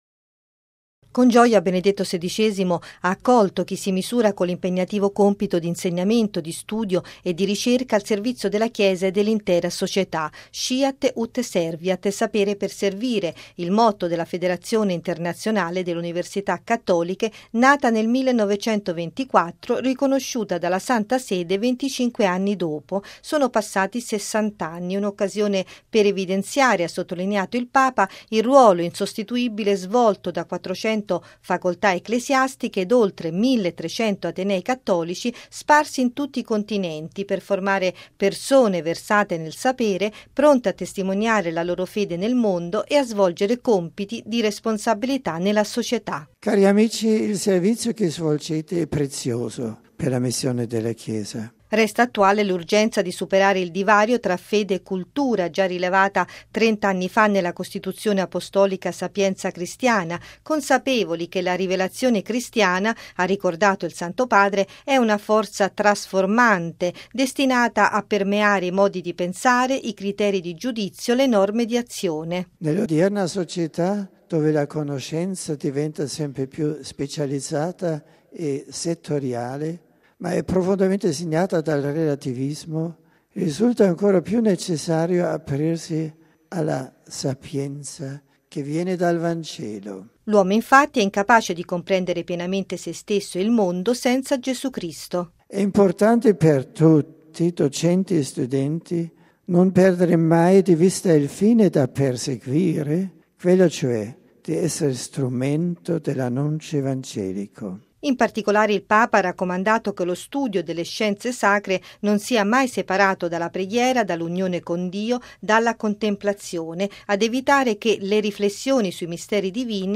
◊   L’importanza del sapere illuminato dalla fede, ne ha parlato stamane Benedetto XVI ricevendo in udienza i partecipanti all’assemblea della Federazione internazionale delle Università cattoliche, insieme ai rettori, docenti e studenti dei Pontifici Atenei Romani.